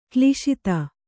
♪ kliśita